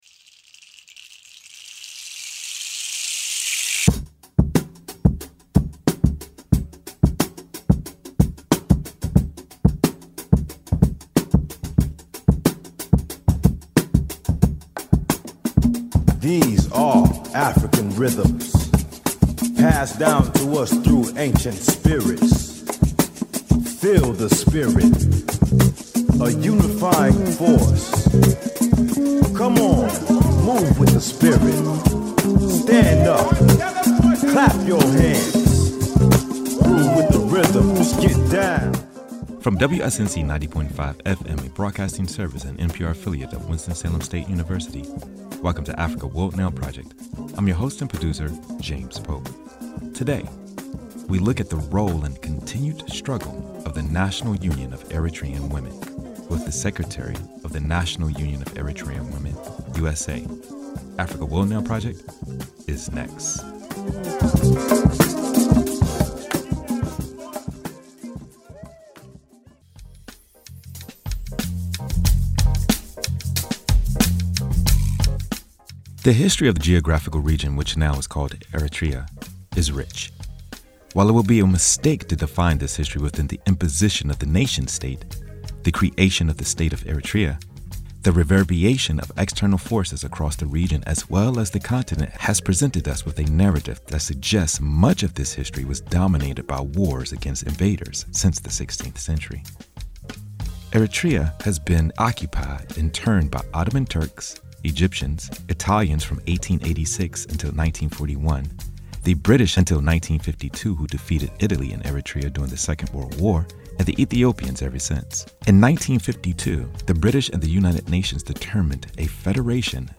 interviews with and presentations from artists, activists, scholars, thinkers, practitioners, and other stake holders